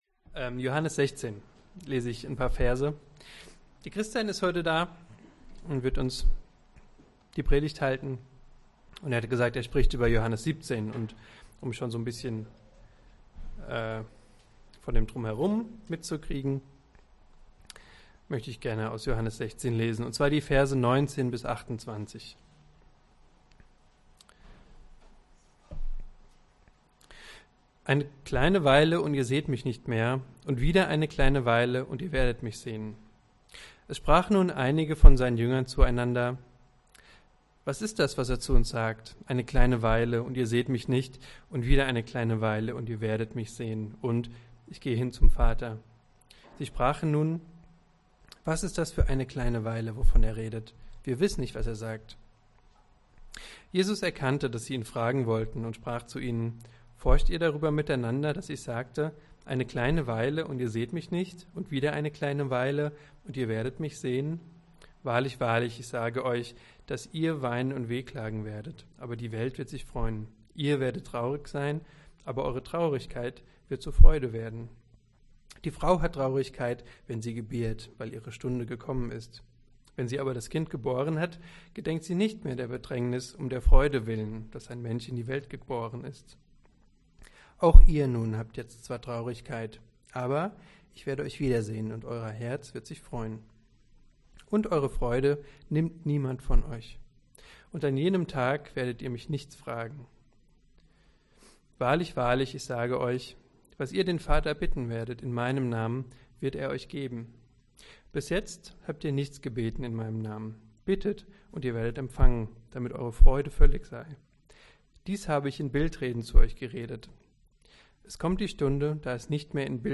Predigt Geduld Veröffentlicht am 4.